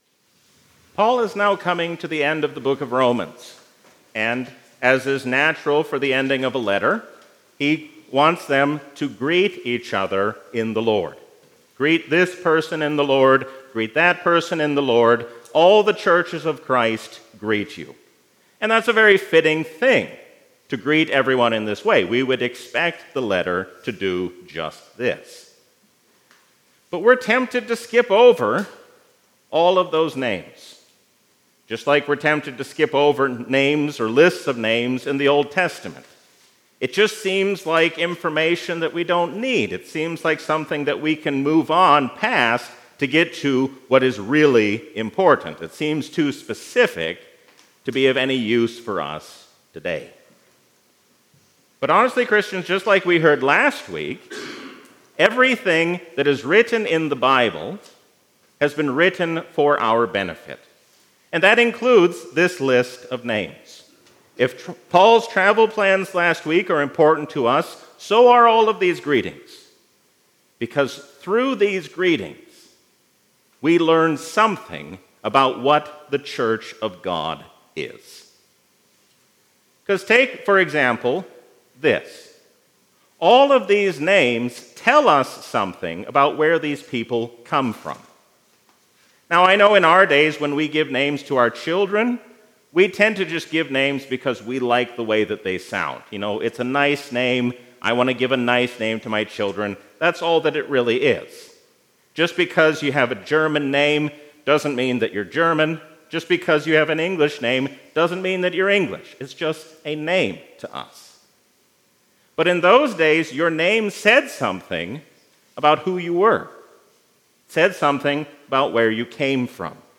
Sermon
A sermon from the season "Trinity 2024." Let us seek to resolve our disputes in true unity and peace, because God has made us one in Jesus Christ.